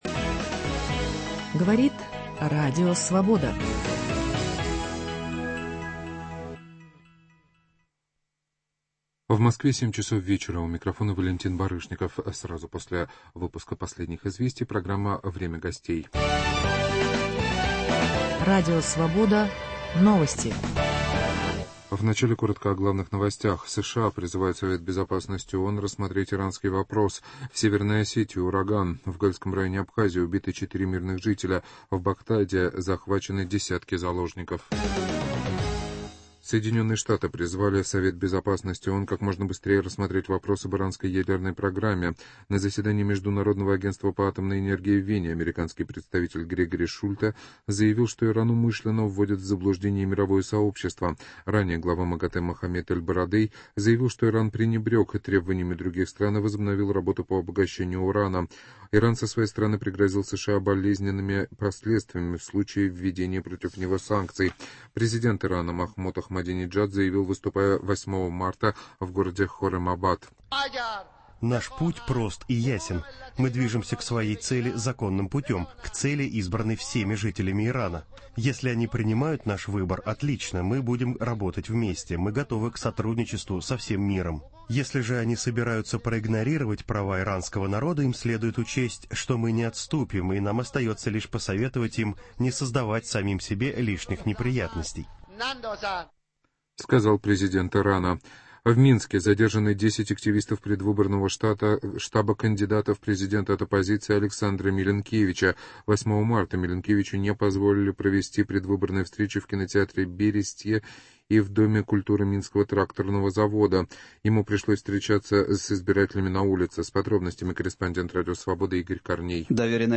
В студии Радио Свобода академик Татьяна Заславская — авторитетный российский социолог, автор нашумевшего в начале восьмидесятых годов «Новосибирского манифеста», в котором давался анализ положения дел в стране.